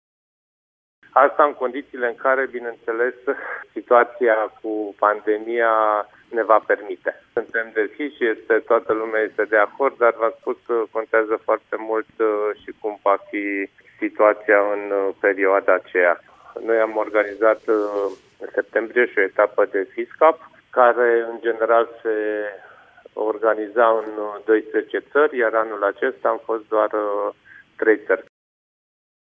Primarul din Râşnov a adăugat că se aşteaptă la un număr record de spectatori, mai ales la Etapa de Cupă Mondială de Echipe.